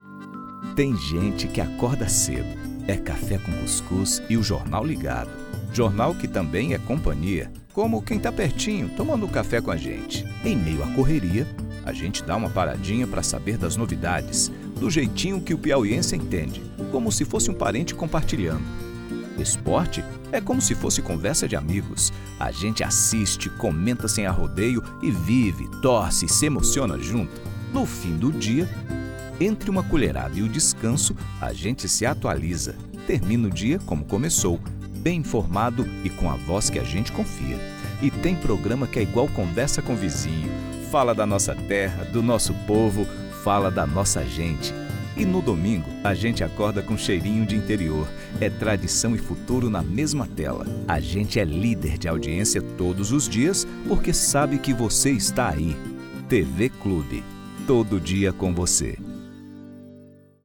Male
Yng Adult (18-29), Adult (30-50)
Radio / TV Imaging